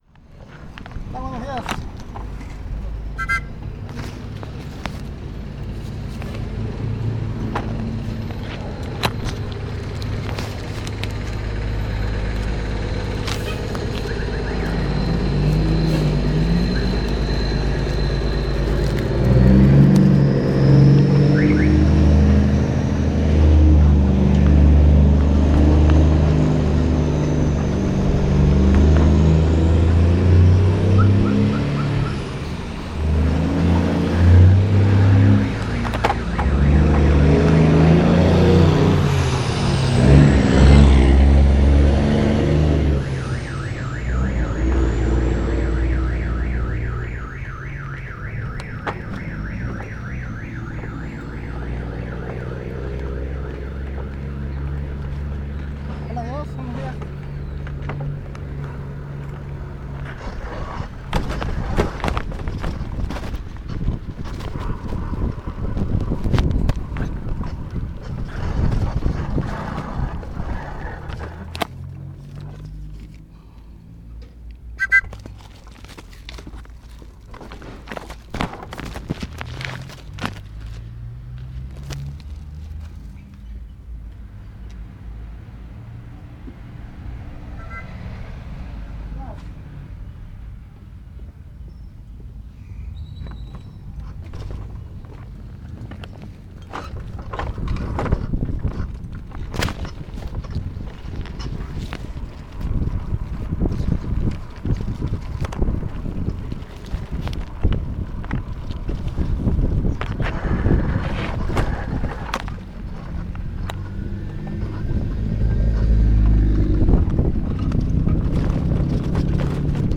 Continuando con el experimento, para celebrar el Día del Cartero y del Empleado Postal, tambien colocamos unos micrófonos a la bicicleta por lo que hemos grabado lo que escucha mientras lleva a su Cartero. Le sugerimos el uso de audífonos para una mayor experiencia auditiva.
Autor: Archivosonoro Fecha: 12 de noviembre de 2014 Lugar: Terán, Tuxtla Gutierrez. Chiapas Equipo: Grabadora digital y micrófonos adaptados en audífonos.